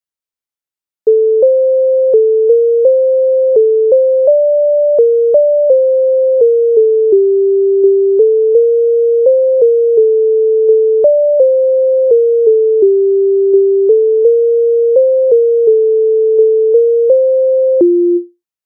Позволь мені мати Українська народна пісня з обробок Леонтовича с.133
Ukrainska_narodna_pisnia_Pozvol_meni_maty.mp3